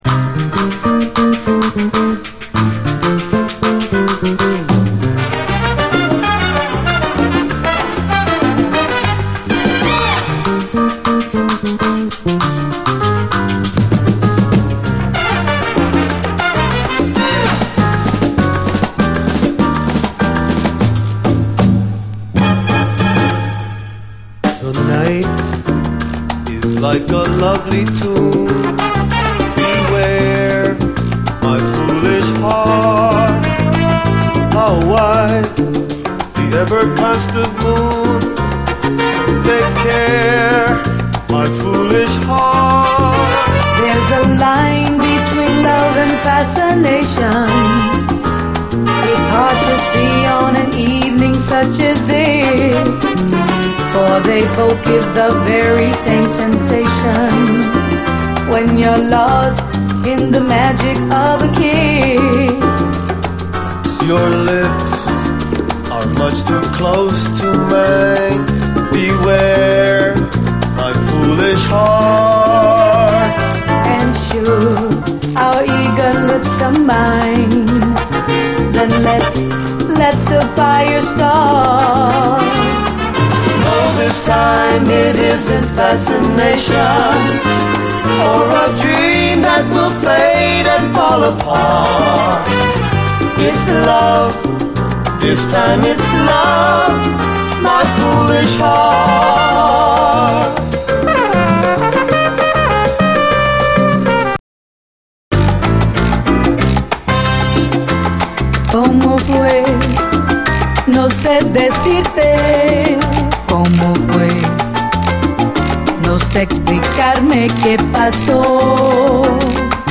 SALSA, LATIN